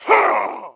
attack_3.wav